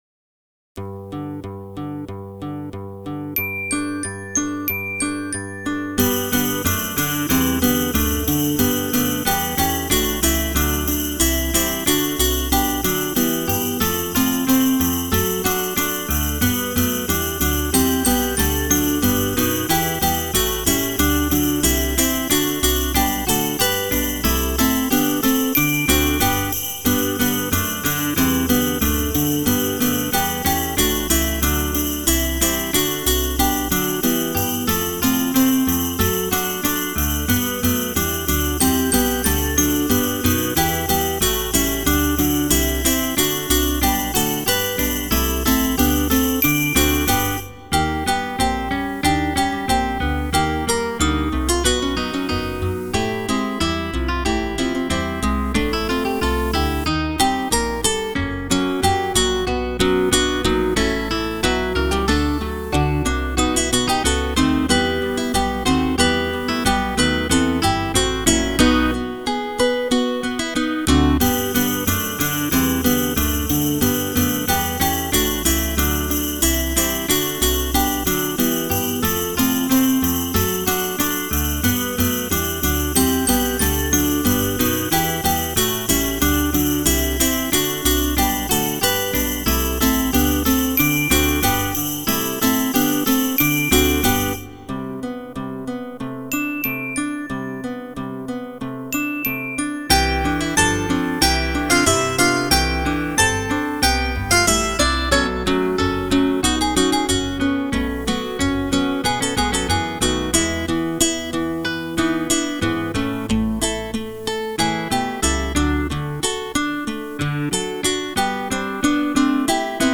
Humoreske für 4 Gitarren